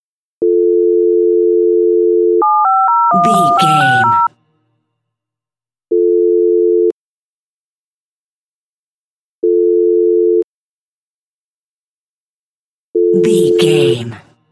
Telephone tone redial 8 numbers
Sound Effects
phone